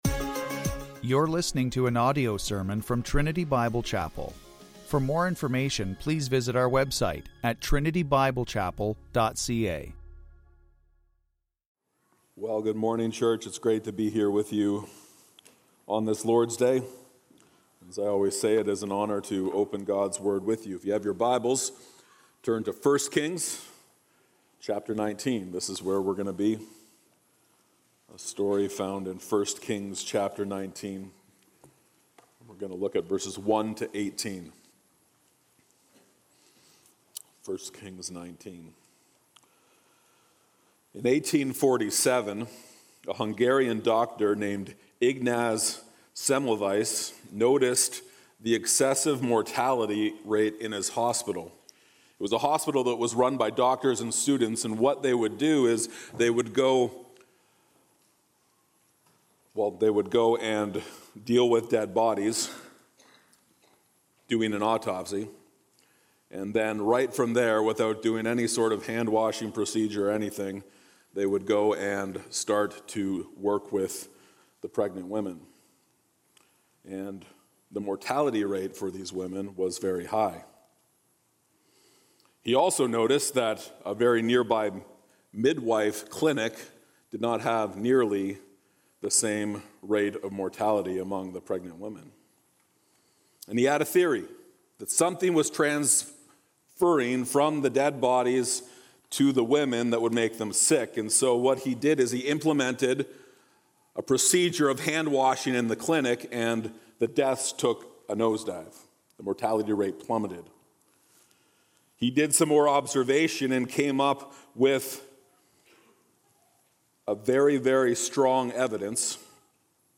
2025 Scripture: 1 Kings 19:1-18 Series: Single Sermons Topics